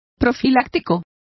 Complete with pronunciation of the translation of prophylactics.